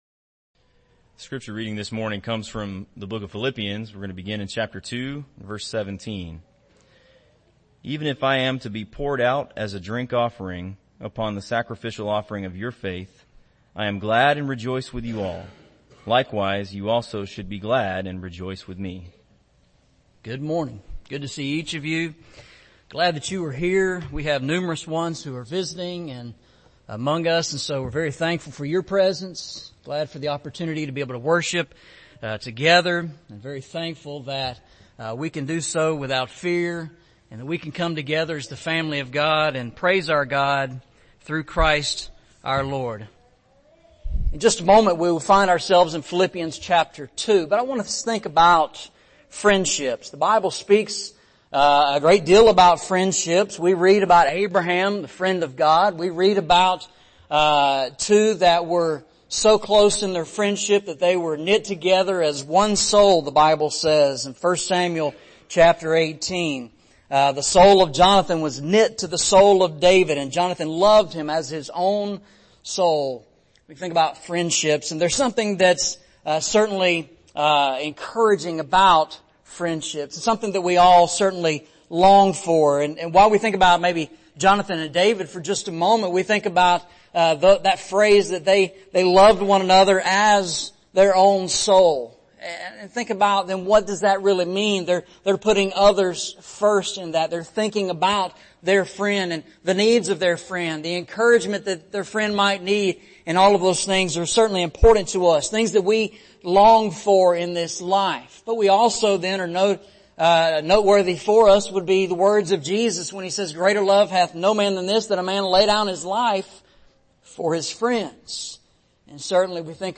Eastside Sermons